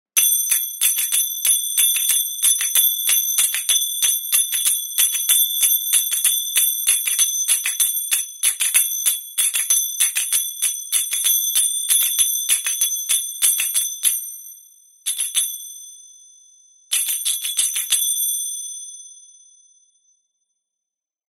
Golpes de crótalos siguiendo un ritmo 02
percusión
crótalo
golpe
ritmo